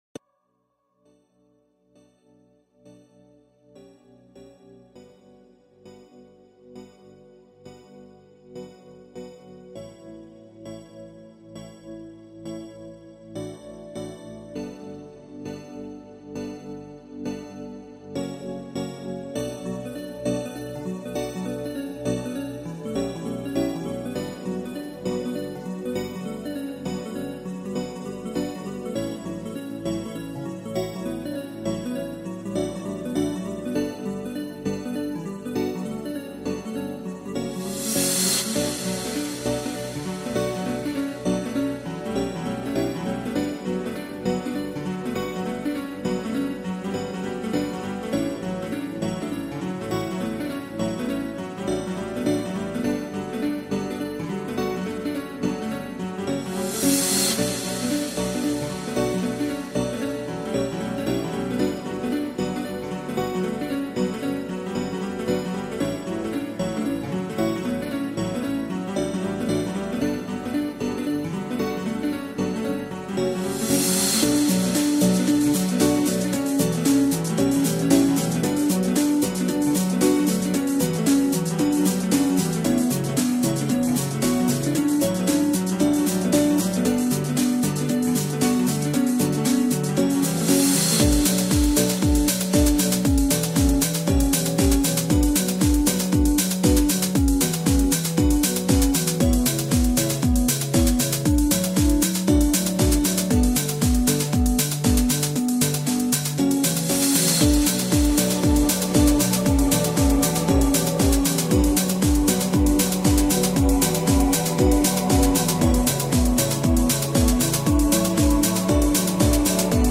This was a remix